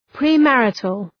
Προφορά
{prı’mærıtəl}